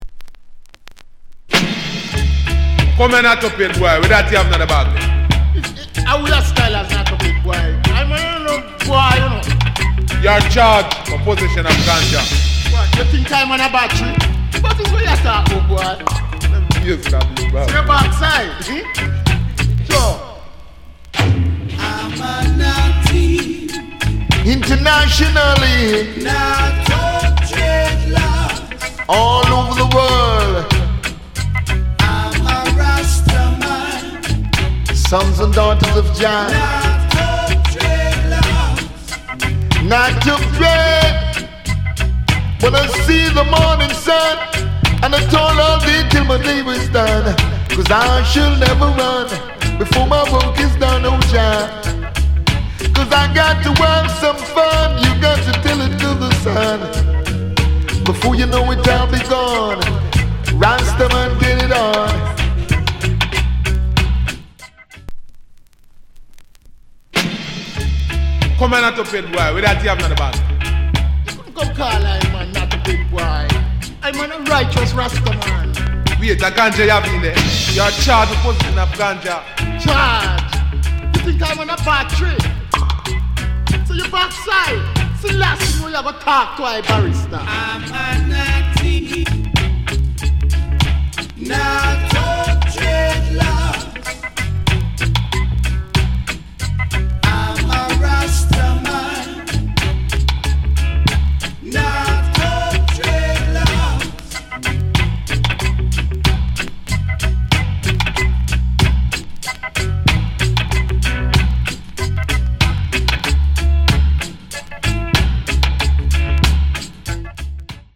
DJ Cut